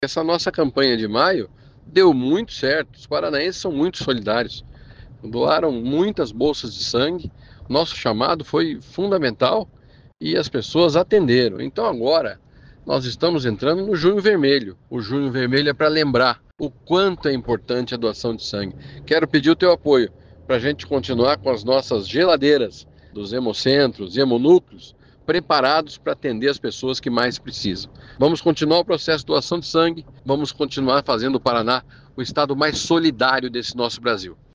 Sonora do secretário da Saúde, Beto Preto, sobre o Junho Vermelho